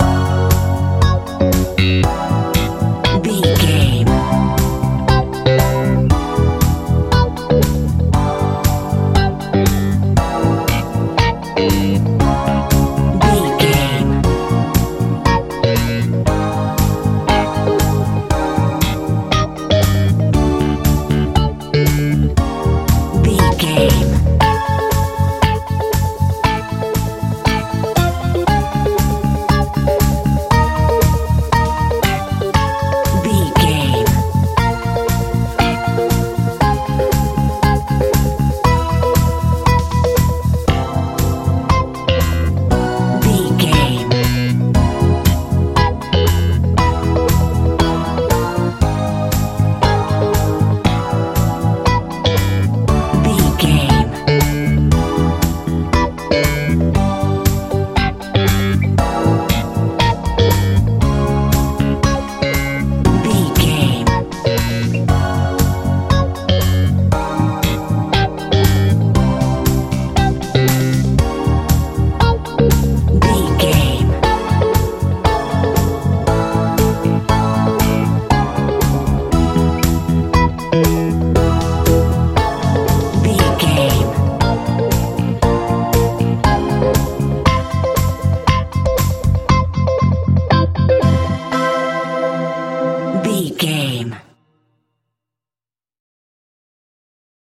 disco feel
Ionian/Major
C♯
groovy
funky
organ
bass guitar
drums